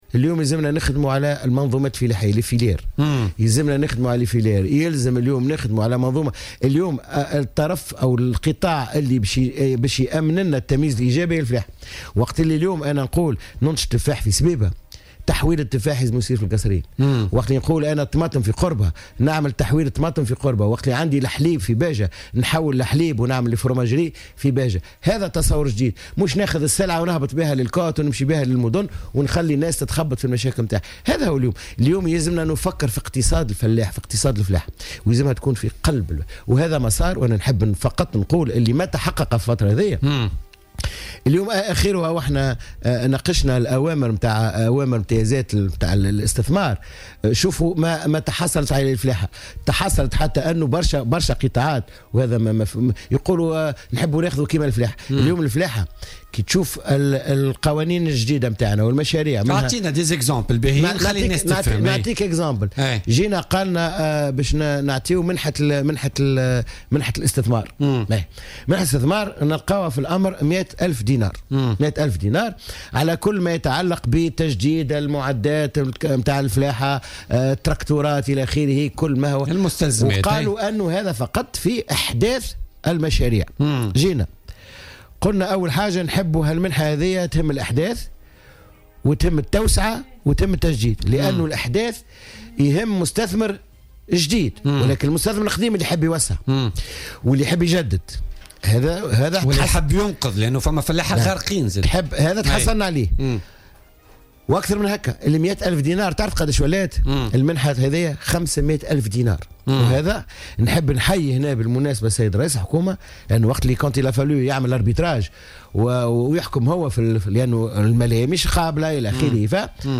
قال وزير الفلاحة والموارد المائية والصيد البحري سمير الطيب، خلال استضافته اليوم في برنامج "بوليتكا"، إن مشروع الحكومة على المستوى الفلاحي يعد سابقة في تونس، خاصة من حيث الاهتمام بالفلاحين.